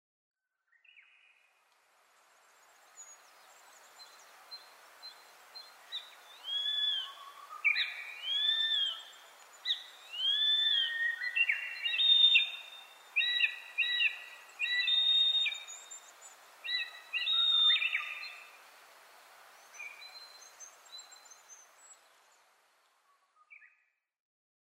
ノスリ　Buteo buteoタカ科
日光市稲荷川中流　alt=730m
Mic: Panasonic WM-61A  Binaural Souce with Dummy Head
他の自然音：ウグイス、キクイタダキ、シジュウカラ